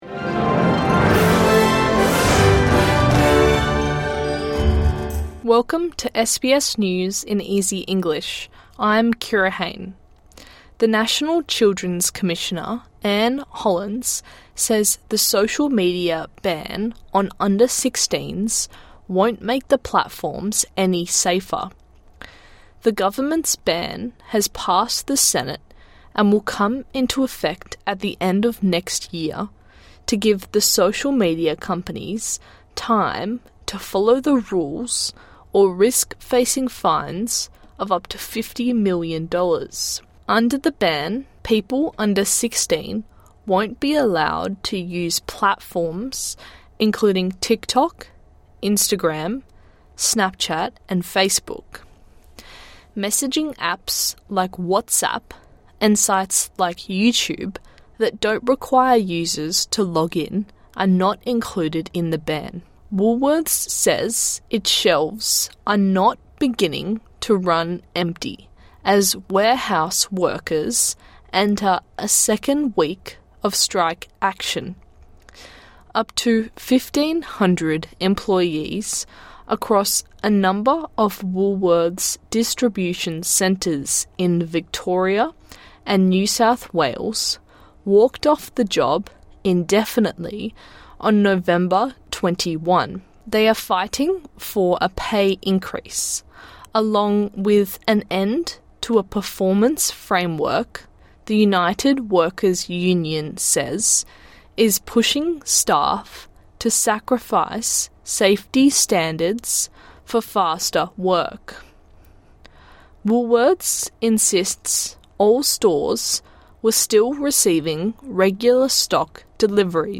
A daily 5 minute news bulletin for English learners and people with a disability.